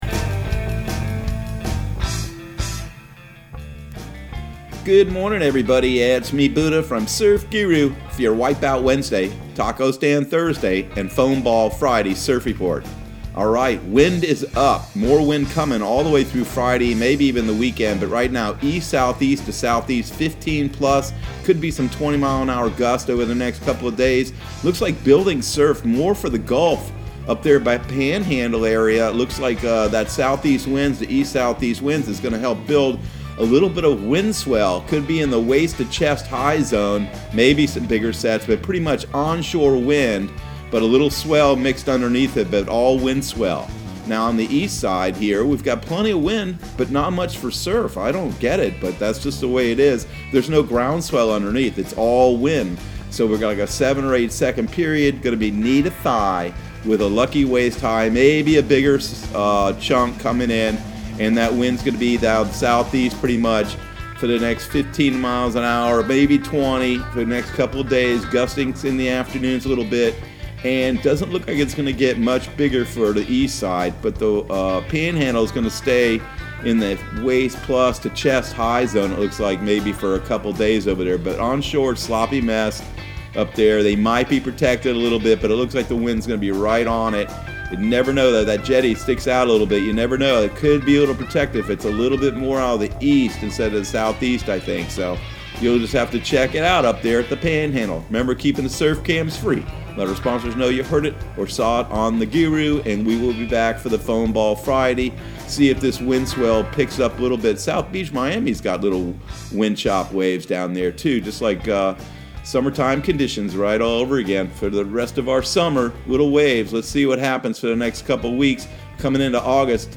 Surf Guru Surf Report and Forecast 07/22/2020 Audio surf report and surf forecast on July 22 for Central Florida and the Southeast.